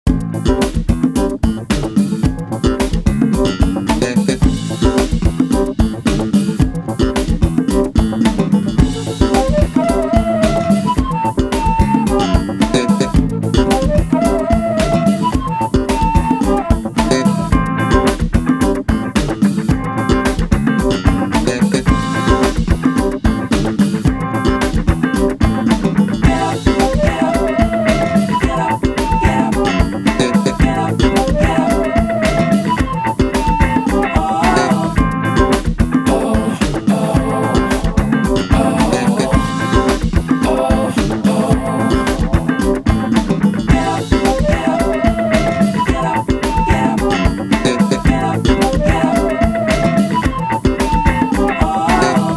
Nahrano do iPadu pres iRig a GarageBand. Vsechno krome basy jsou samply.
Pěknej zvuk basy.
Diky :-) V GarageBandu nejde ten zvuk nijak upravit - je to teda nahrany dratem primo do iRigu. Zadnej reverb nebo kompresor atd..